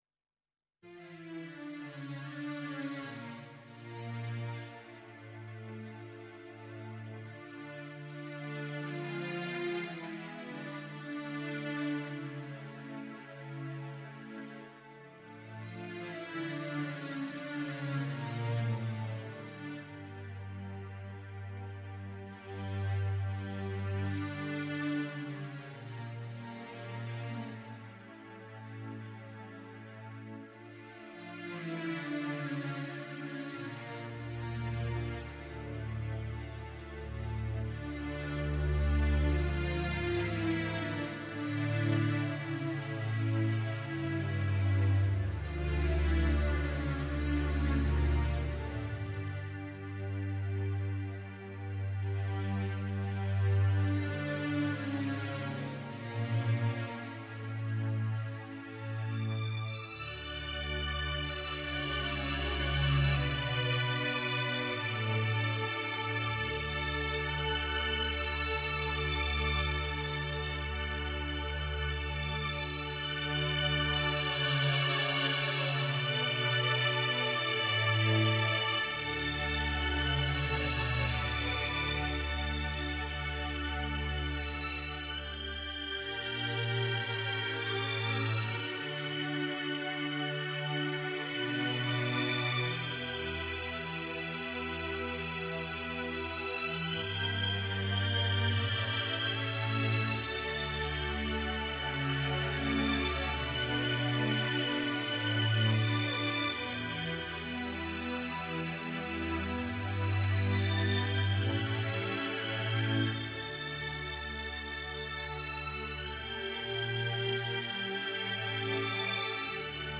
آهنگ بی کلام و زیبای Chamol Al Maaseicha از یهودا اشلگ (بعل هسولام)